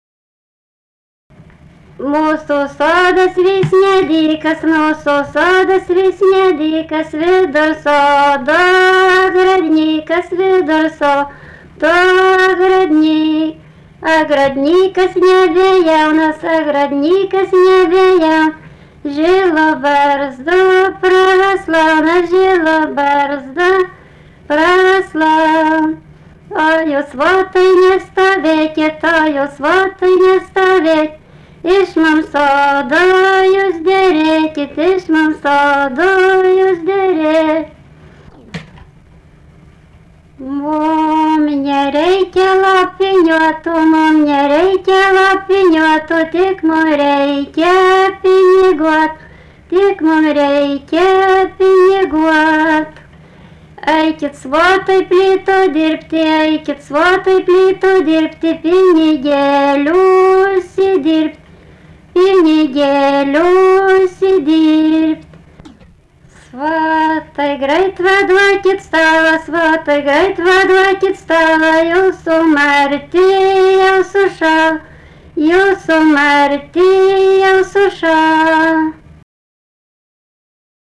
daina, vestuvių
Antašava
vokalinis